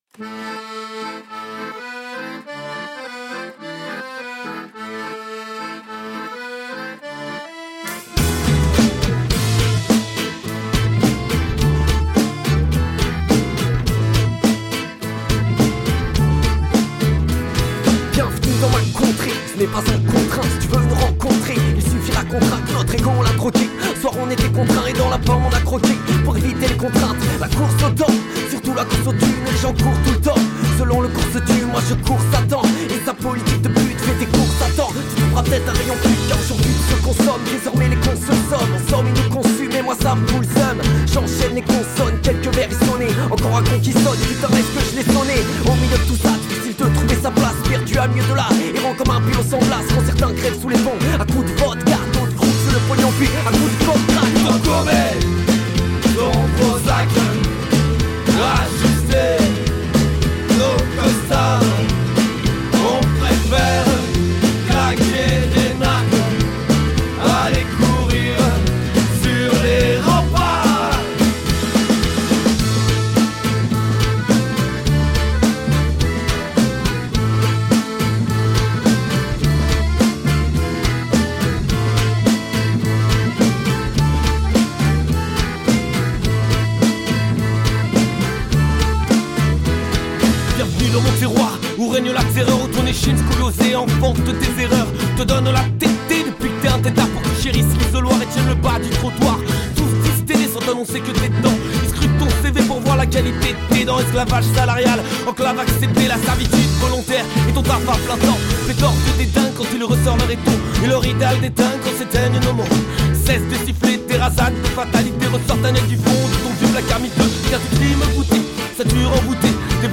Grenoble France rock rap and musette band
a really fascinating mixture of sounds